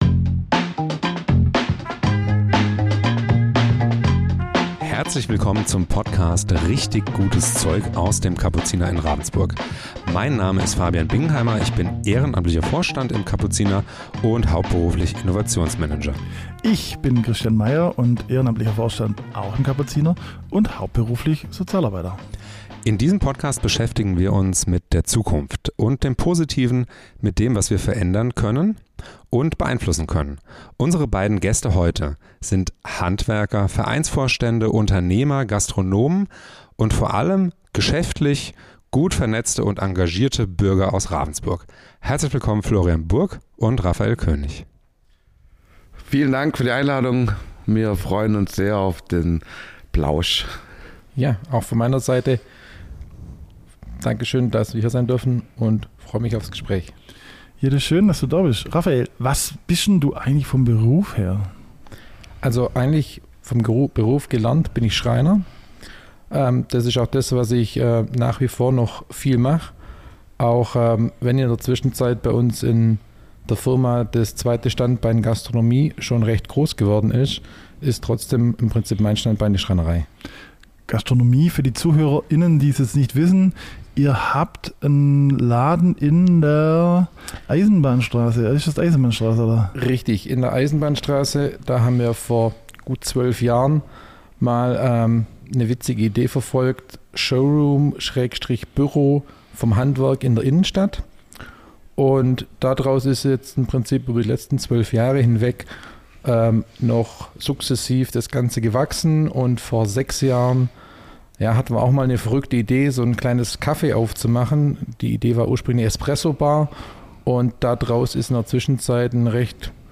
Wir sprechen über Familiengeschichten, den Zauber des Handwerks, moderne Mitarbeiterführung, Azubis, Cafégründung – und ja: auch über ihre Rolle während Corona, als sie kurzerhand eine der größten Teststationen der Region aufgebaut haben. Ein Gespräch voller Haltung, Inspiration und Humor.